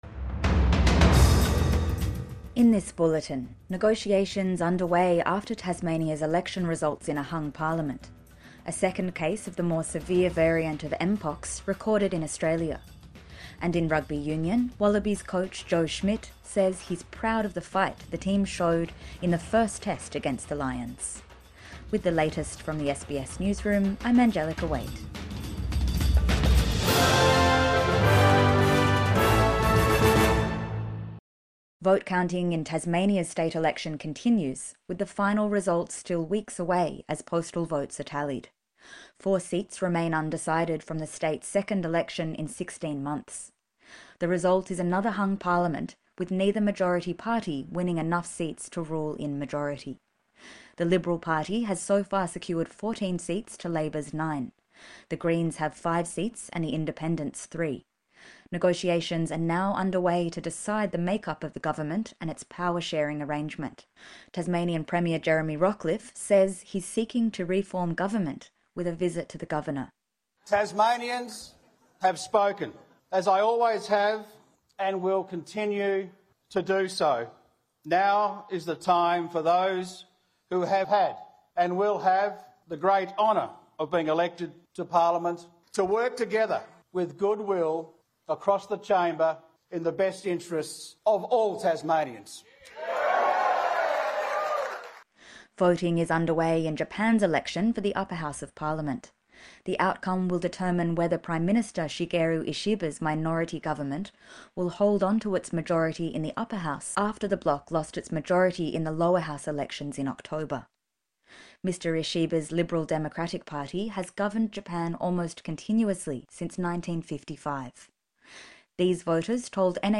Negotiations underway after Tasmania's election result | Midday News Bulletin 20 July 2025